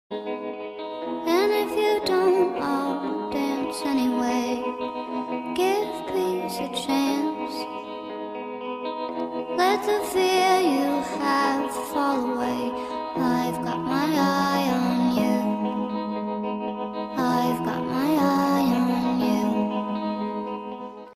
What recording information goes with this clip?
infront of the crowd